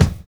NY 11 BD.wav